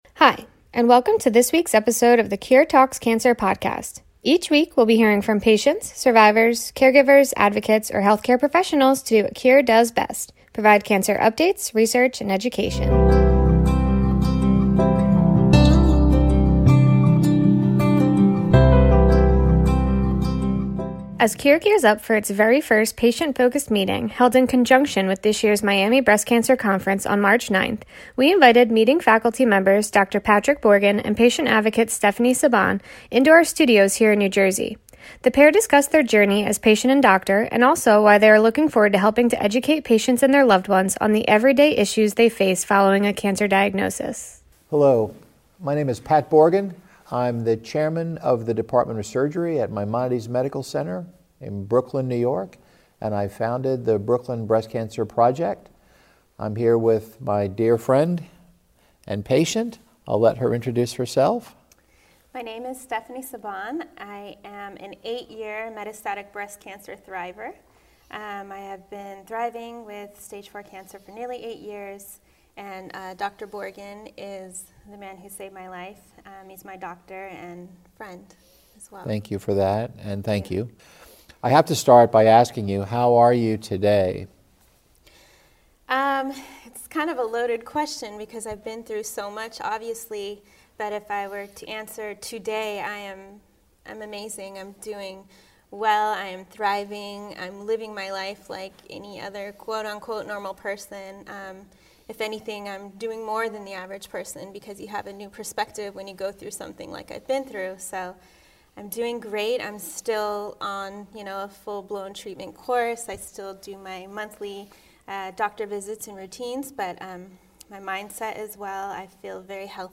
The pair discussed their journey as patient and doctor, and also why they are looking forward to helping to educate patients and their loved ones on the everyday issues they face following a cancer diagnosis.